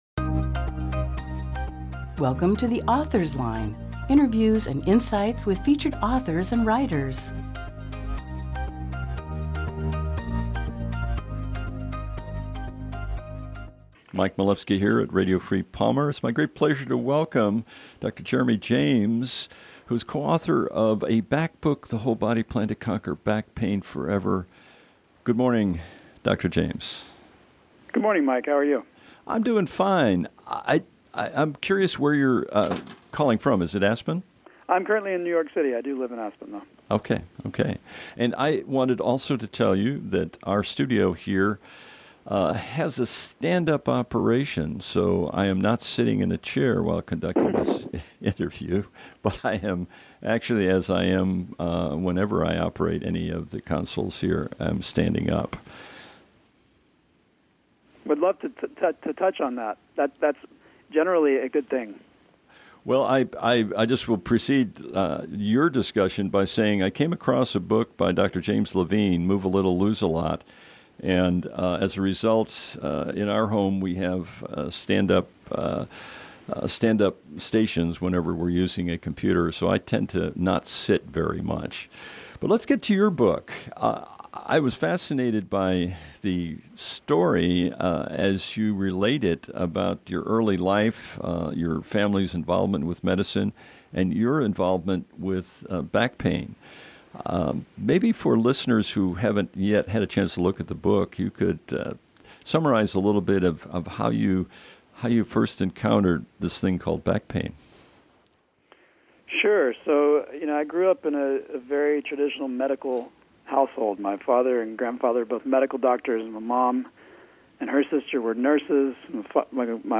Aug 7, 2018 | Author Interviews